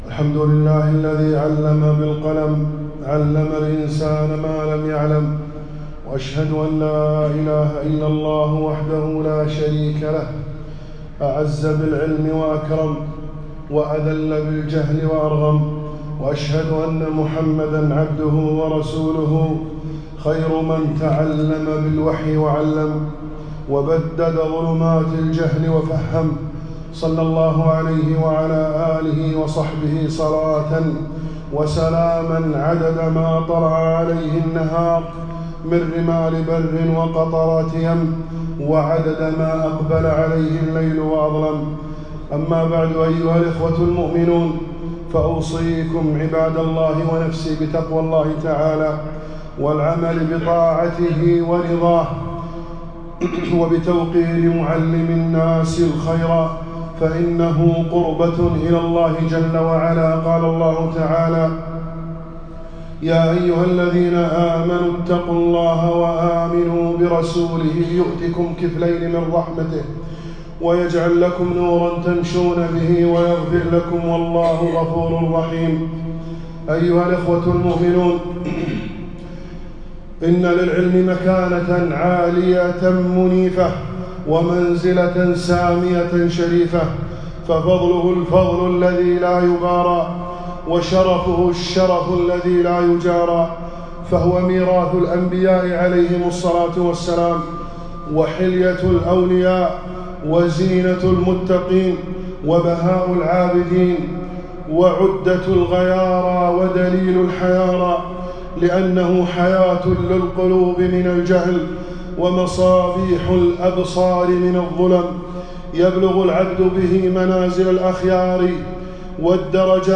خطبة - فضل العلم وأهله ووصايا للمعلمين والطلاب